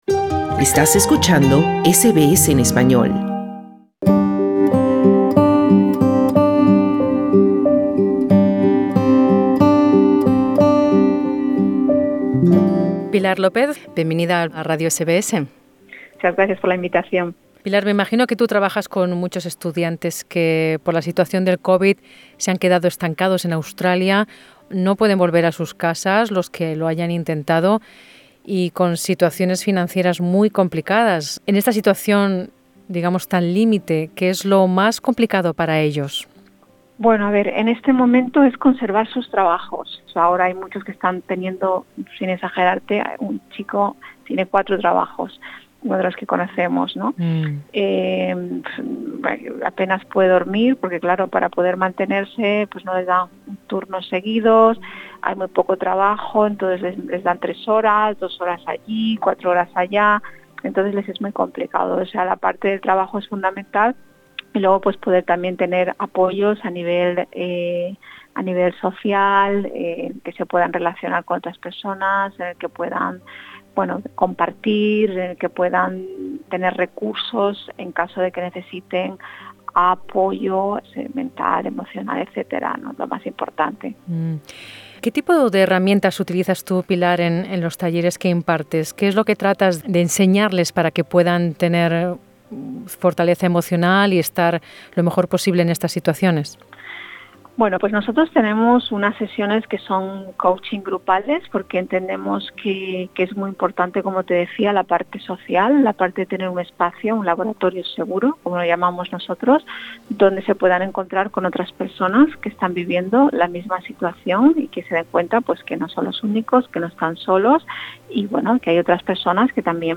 Escucha el podcat con la entrevista presionando la imagen principal.